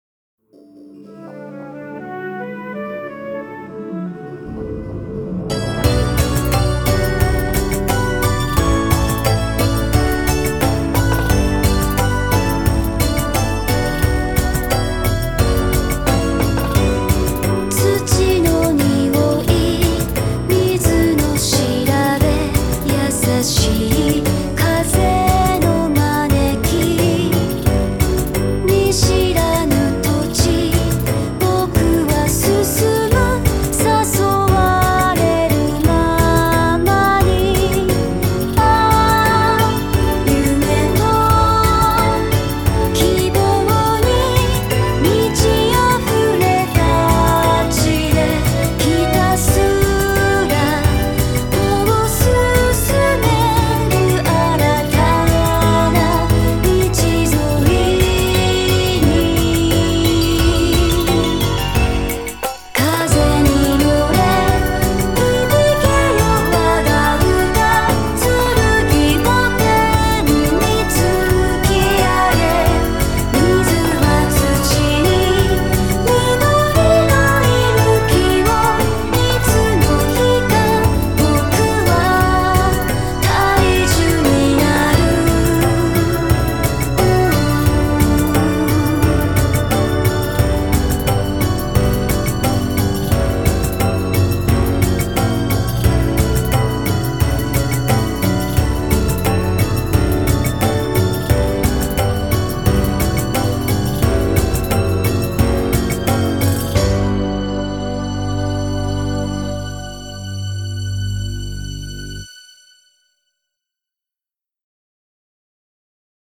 BPM88
Audio QualityPerfect (High Quality)
Genre: LAND BALLAD.